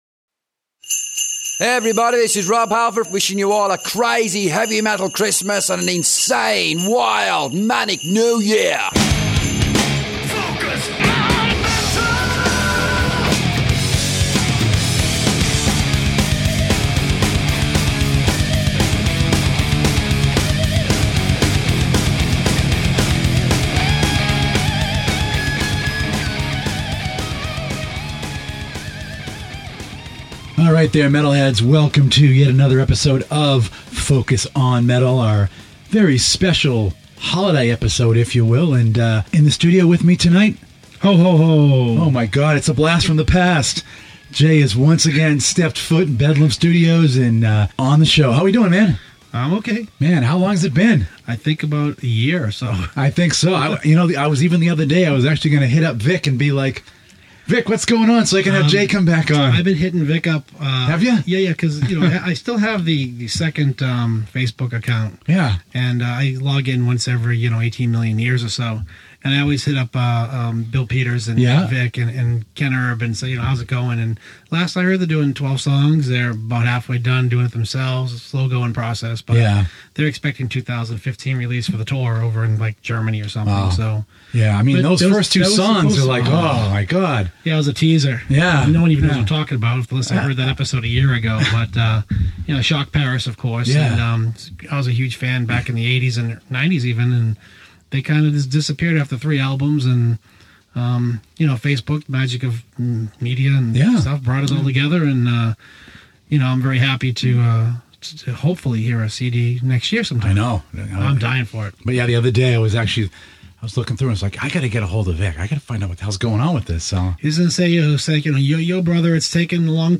Sorry in advance about the voice, it’s still not back yet.